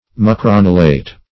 Mucronulate \Mu*cron"u*late\, a. Having, or tipped with, a small point or points.
mucronulate.mp3